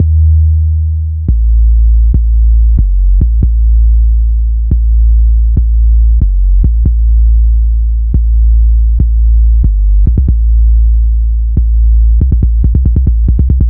Exodus - 808 Bass.wav